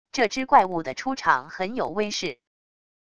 这只怪物的出场很有威势wav音频生成系统WAV Audio Player